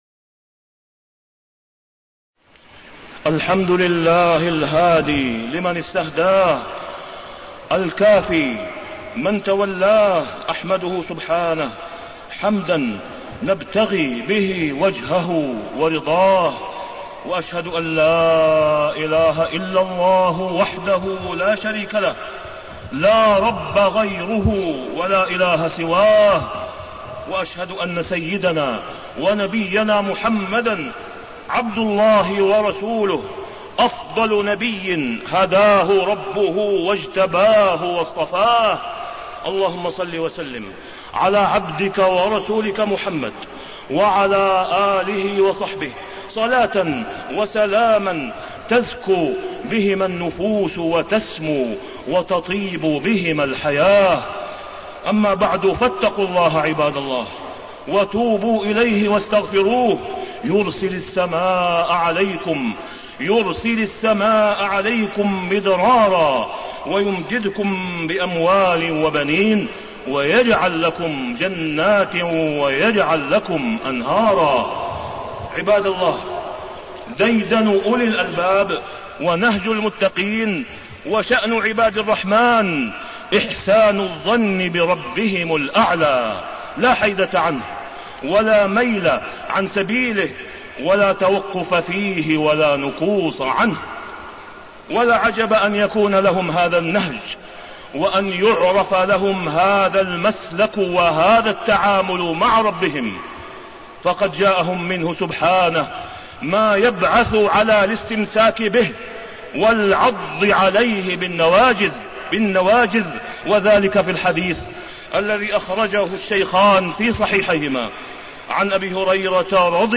تاريخ النشر ٢٥ شوال ١٤٣٢ هـ المكان: المسجد الحرام الشيخ: فضيلة الشيخ د. أسامة بن عبدالله خياط فضيلة الشيخ د. أسامة بن عبدالله خياط حسن الظن بالله وآثاره